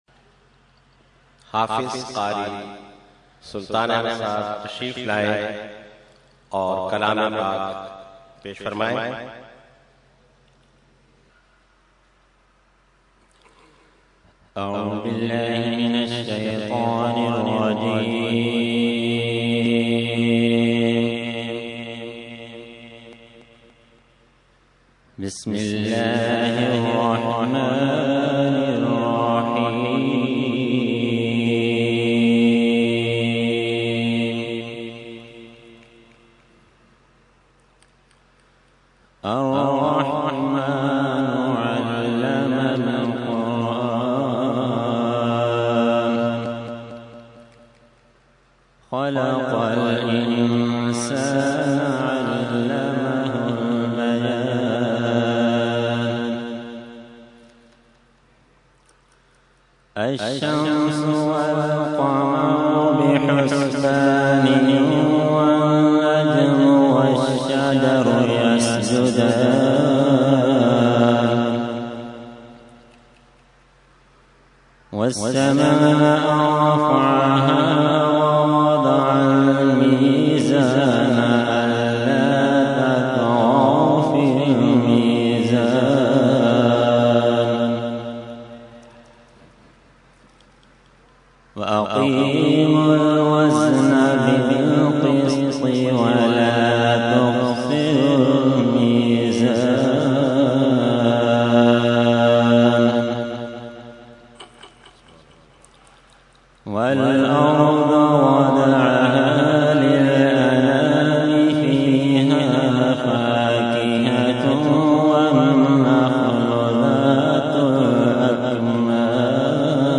Qirat – Urs Makhdoome Samnani 2012 – Dargah Alia Ashrafia Karachi Pakistan